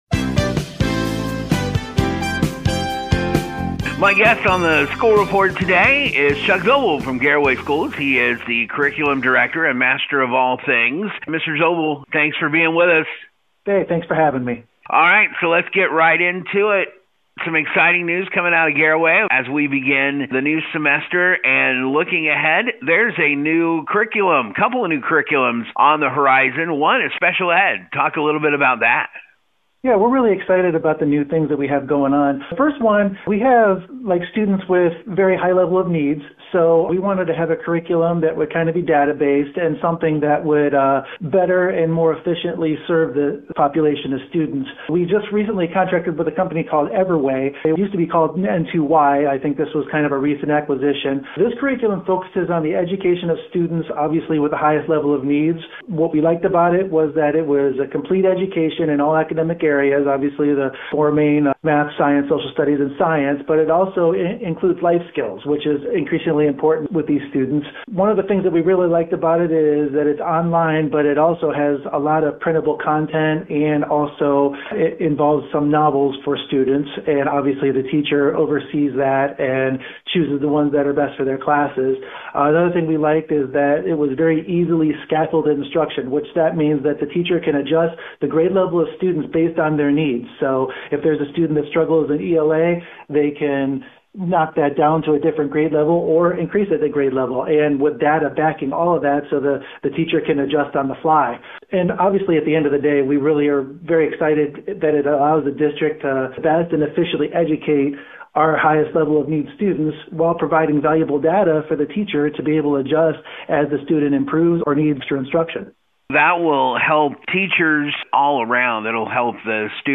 SCHOOL REPORT